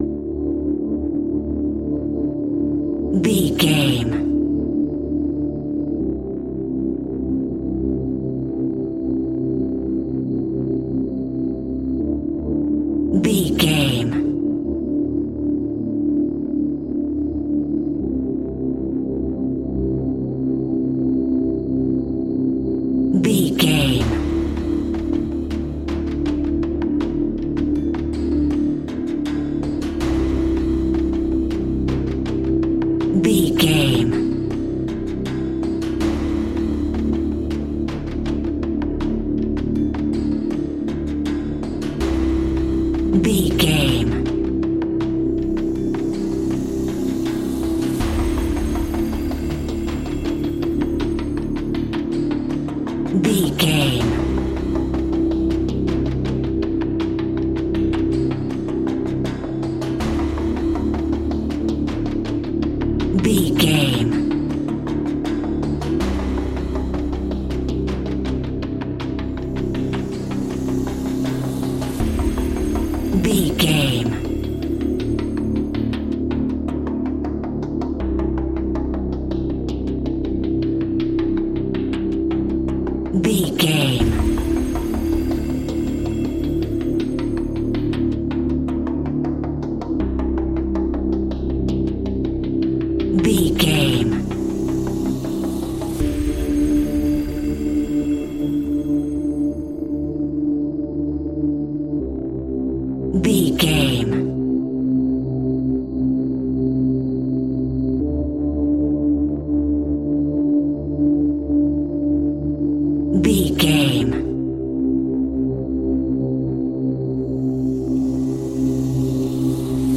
In-crescendo
Thriller
Aeolian/Minor
scary
ominous
eerie
ticking
electronic music
Horror Pads
horror piano
Horror Synths